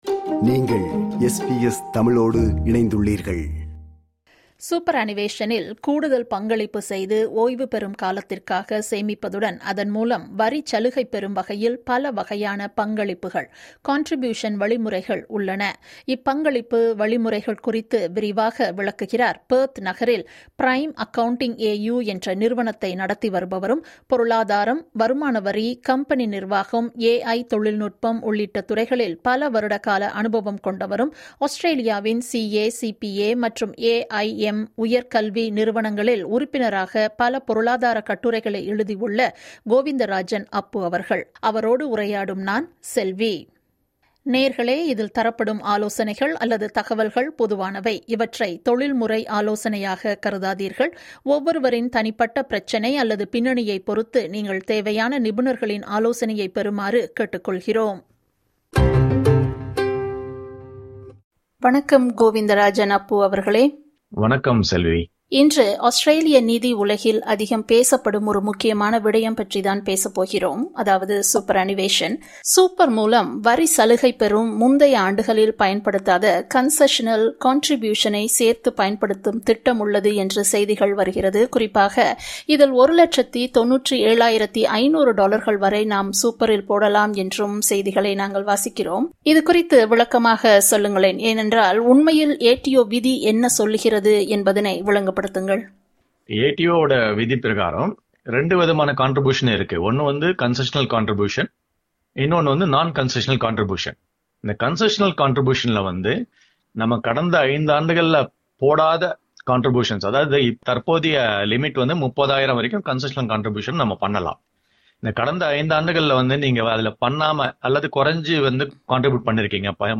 SBS தமிழ் ஒலிபரப்பை திங்கள், புதன், வியாழன் மற்றும் வெள்ளி ஆகிய நாட்களில் மதியம் 12 மணிக்கு நேரலையாக SBS South Asian எனும் டிஜிட்டல் அலையிலும், திங்கள், புதன், வெள்ளி மற்றும் ஞாயிறு இரவு 8 மணிக்கு SBS Radio 2 வழியாகவும் கேட்கலாம்.